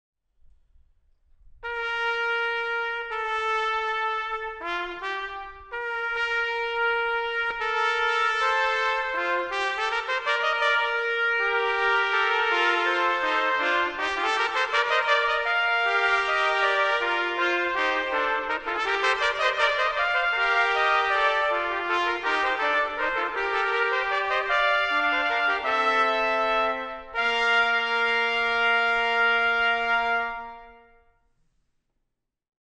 Voicing: Trumpet Trio